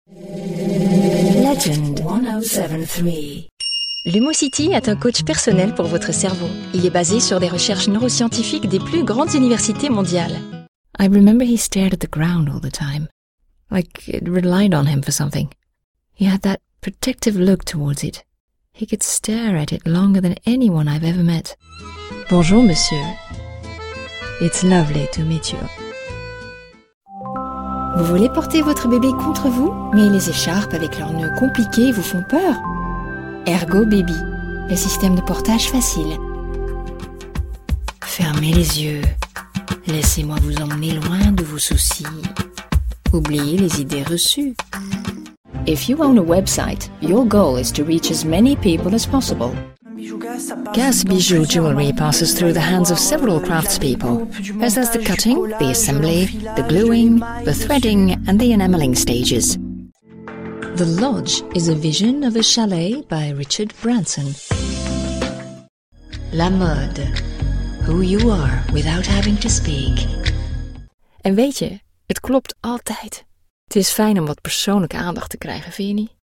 Dutch French and neutral English voice-over talent with professional home studio.
Sprechprobe: Sonstiges (Muttersprache):
European voiceover artist with an international sound: warm, reassuring, rich, engaging, classy.